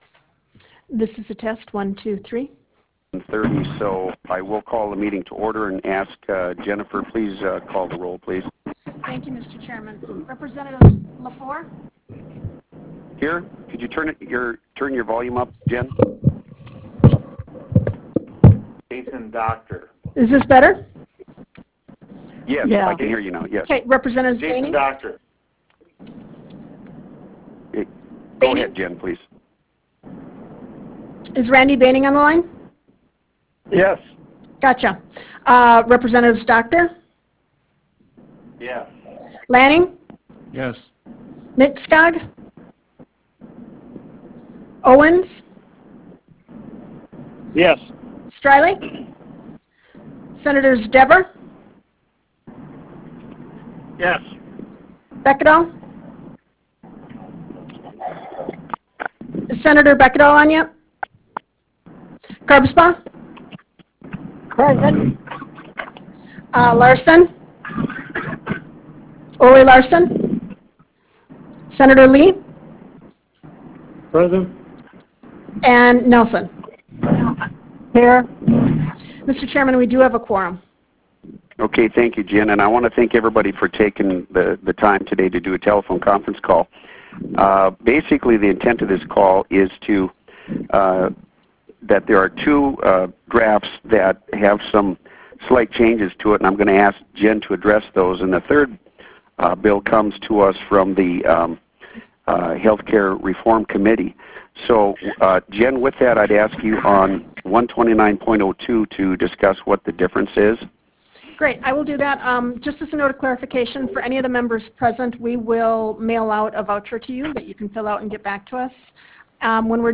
This meeting will be conducted by teleconference call connection.
Harvest Room State Capitol Bismarck, ND United States